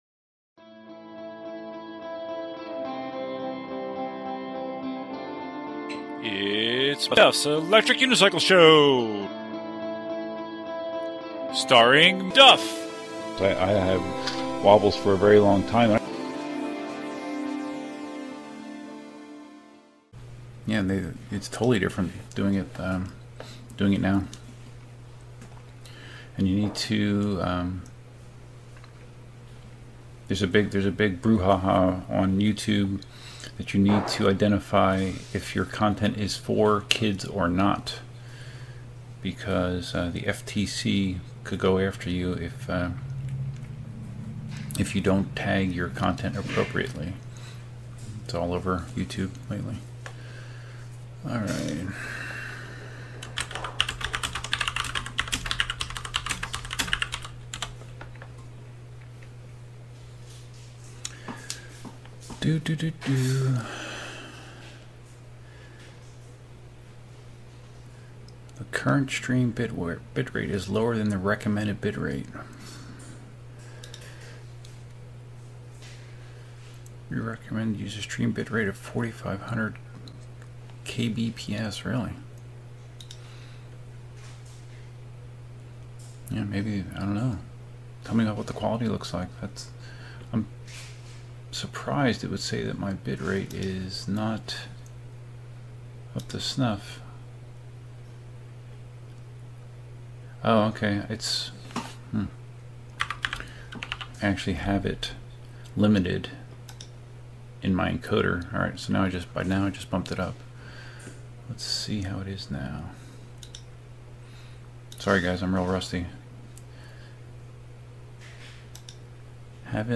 A soft, unscheduled, unscripted reboot of the PEV Live Stream!